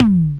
Index of /kb6/Akai_XR-20/Tom